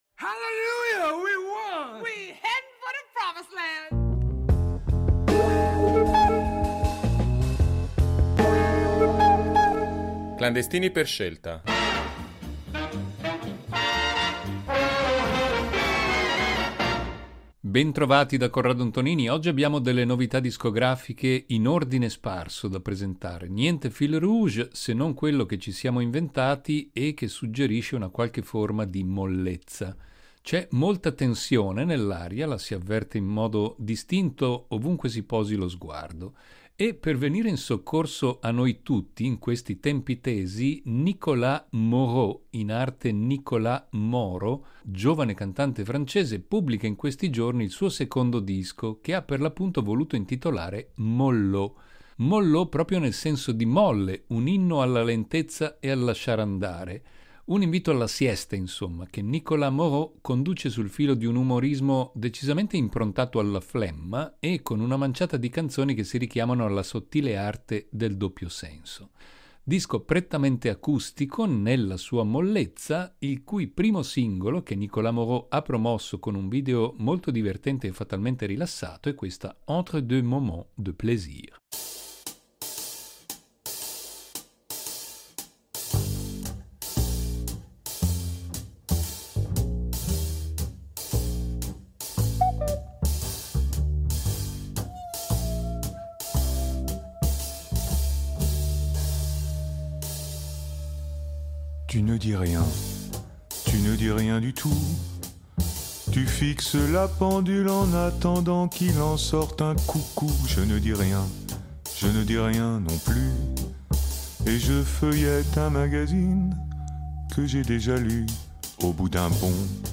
Cinque novità discografiche all’insegna della mollezza o della rilassatezza nella puntata odierna di “Clandestini per scelta”.